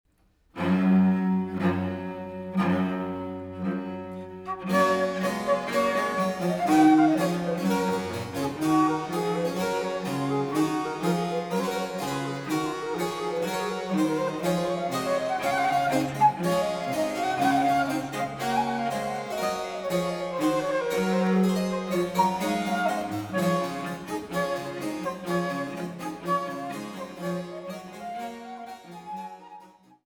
Traversflöte
Cembalo
Vite